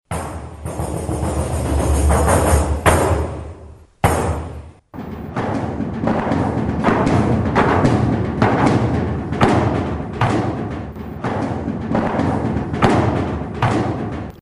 Добулбас